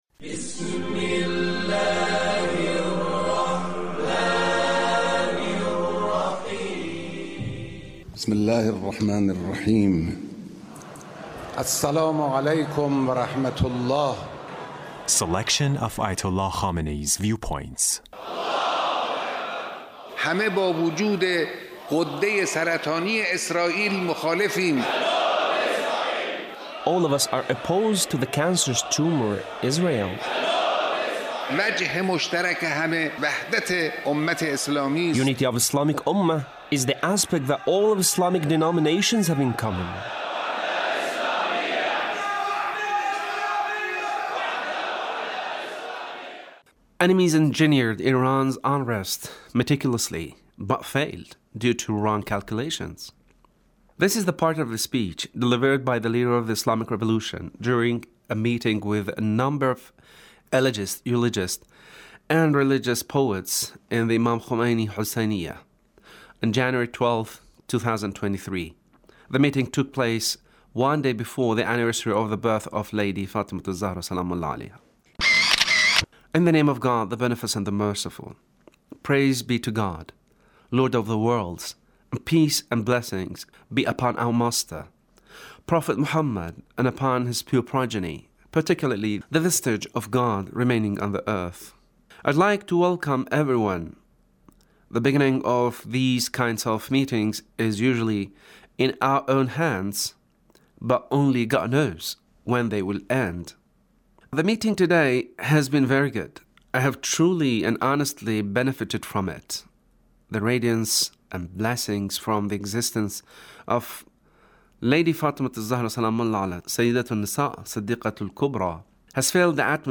Leader's Speech (1619)